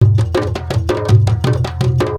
PERC 09.AI.wav